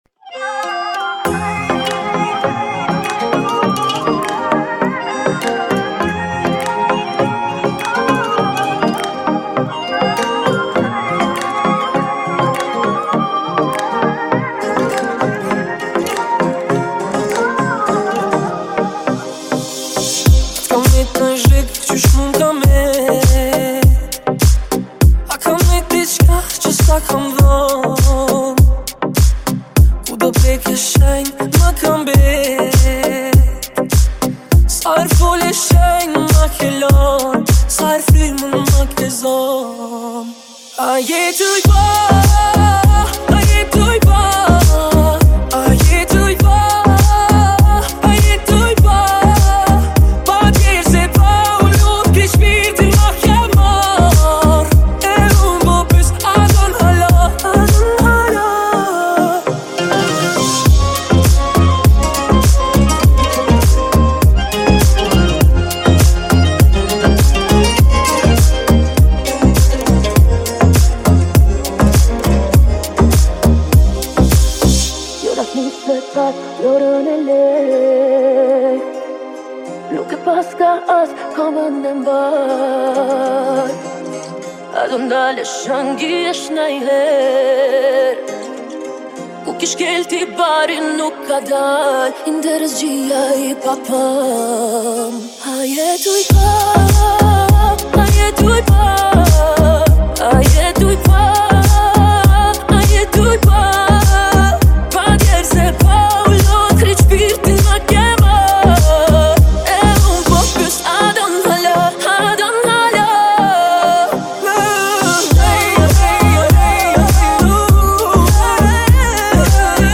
это яркая и энергичная композиция в жанре поп и EDM